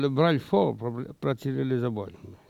Il crie pour attirer l'essaim d'abeilles
Langue Maraîchin
Catégorie Locution